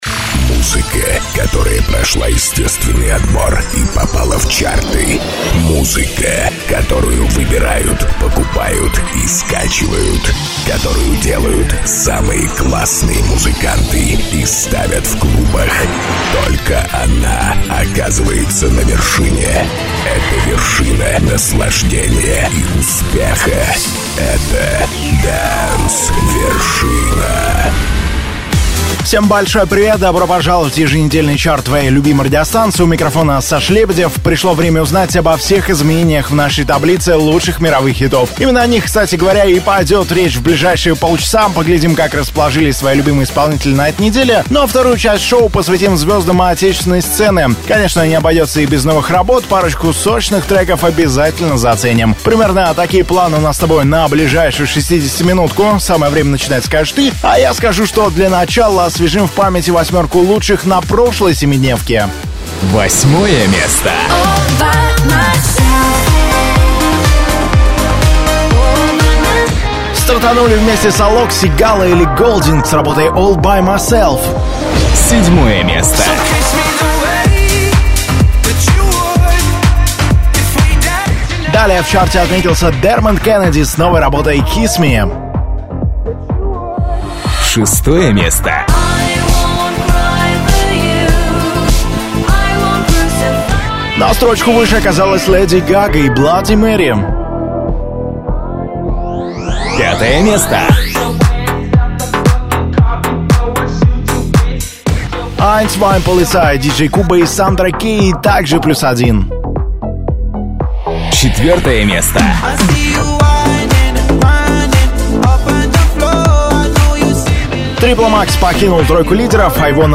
Dance Вершина - еженедельный хит-парад популярной музыки.